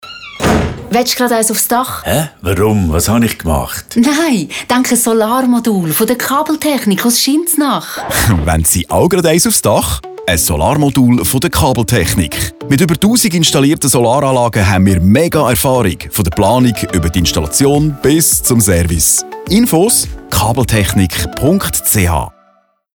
Ein nerviger Spot auf Radio Argovia soll weitere Interessenten auf uns aufmerksam machen.
Radiospot 23s